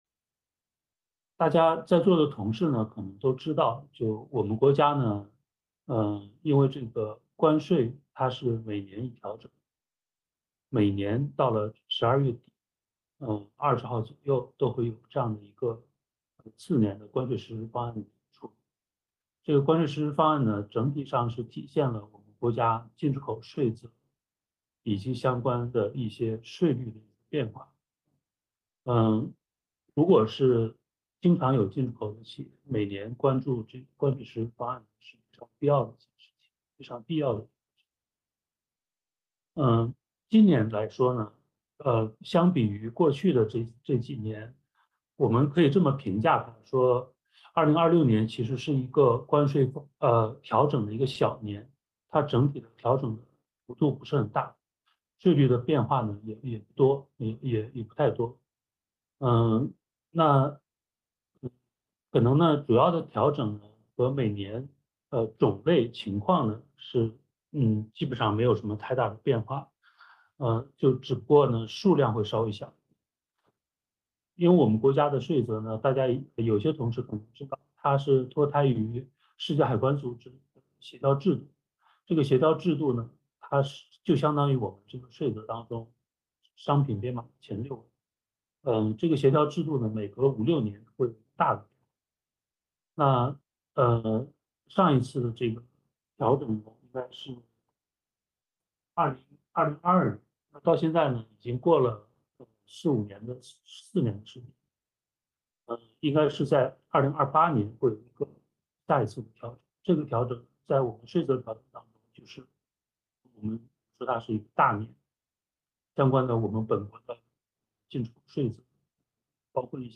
视频会议
互动问答